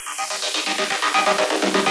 RI_ArpegiFex_125-02.wav